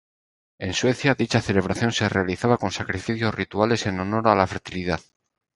ce‧le‧bra‧ción
/θelebɾaˈθjon/